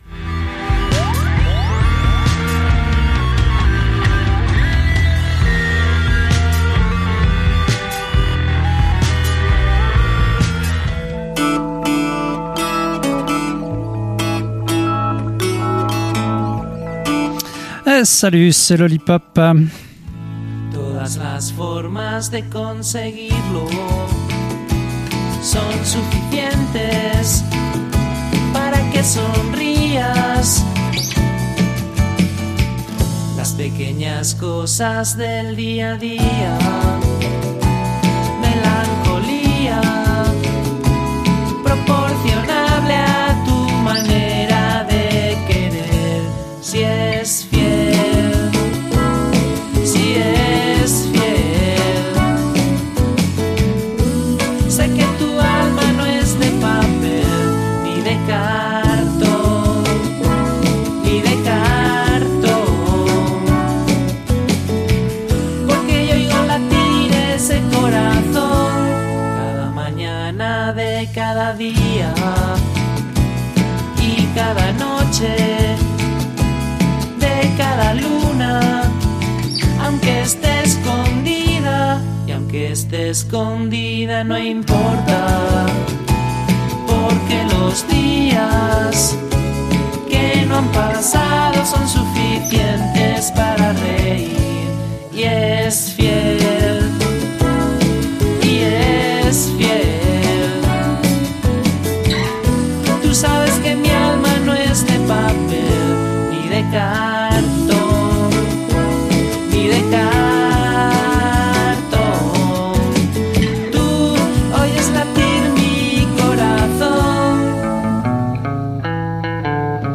**Os recomiendo que lo veáis en video, porque el audio es regular y así os podéis guiar por los subtí